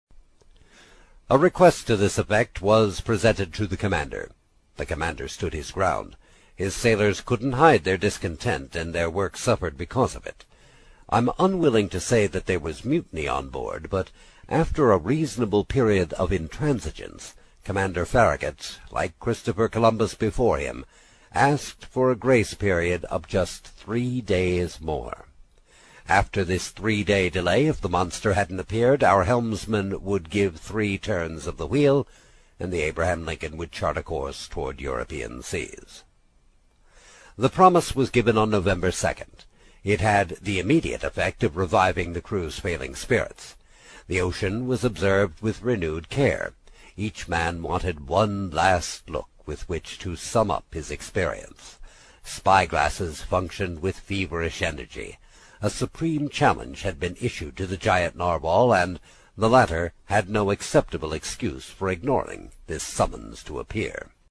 在线英语听力室英语听书《海底两万里》第55期 第5章 冒险活动(10)的听力文件下载,《海底两万里》中英双语有声读物附MP3下载